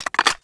p90_clipin.wav